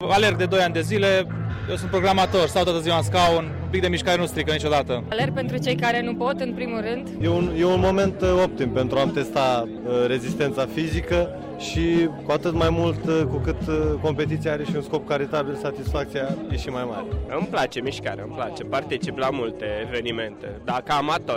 La aceasta ora se desfasoara maratonul Wings for Life.